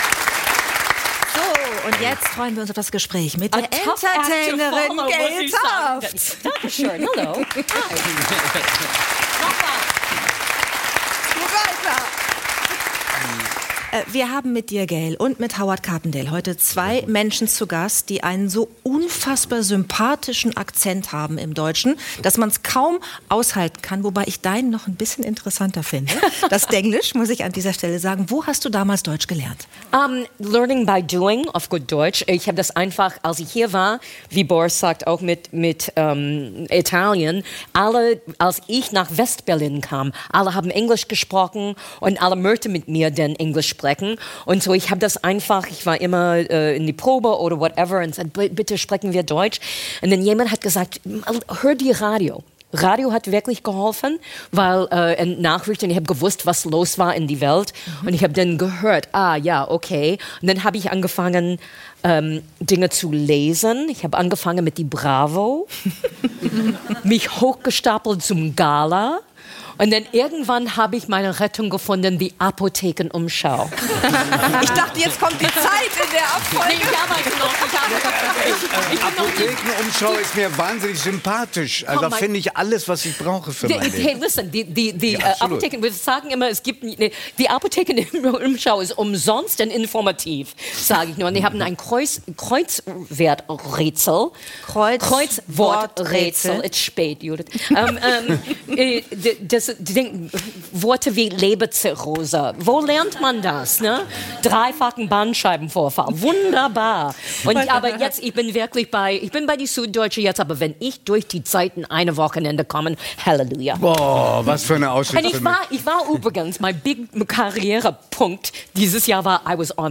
Gayle Tufts – Entertainerin ~ 3nach9 – Der Talk mit Judith Rakers und Giovanni di Lorenzo Podcast